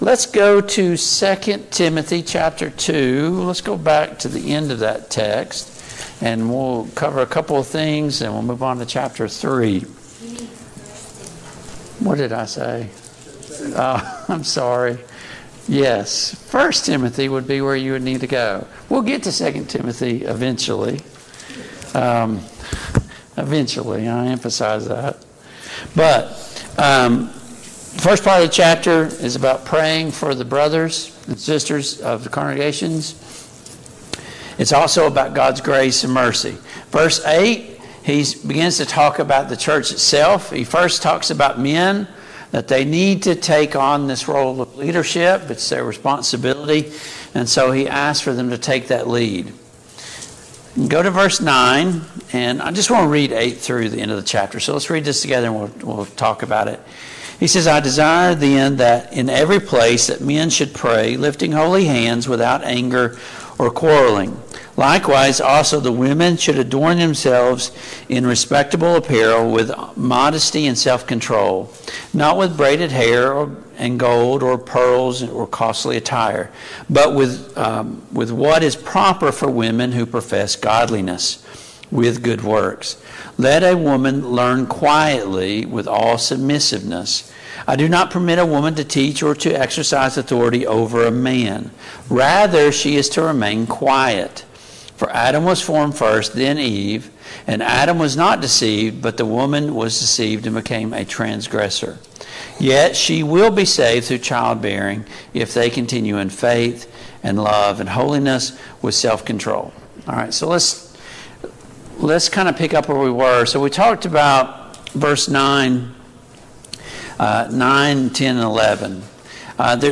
Service Type: Mid-Week Bible Study